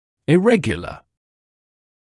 [ɪ’regjələ][и’рэгйэлэ]неправильный; имеющий неправильную форму; аномальный